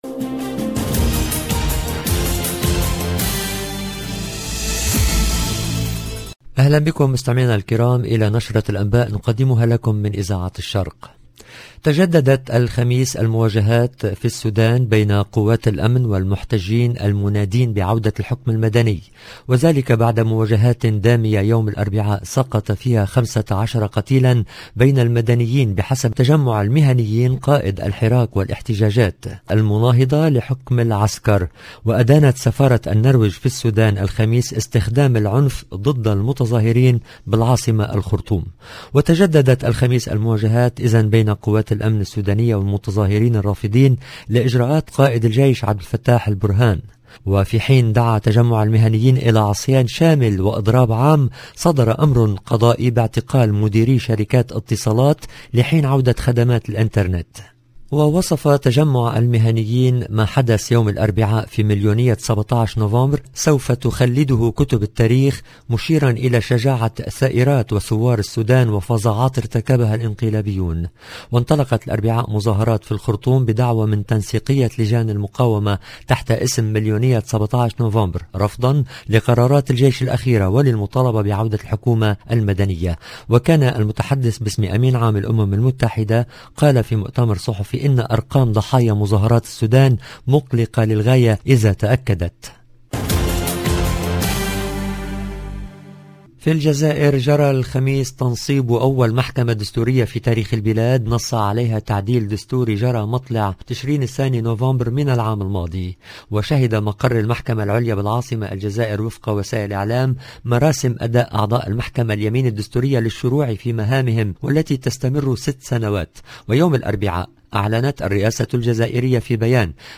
LE JOURNAL EN LANGUE ARABE DU SOIR DU 18/11/21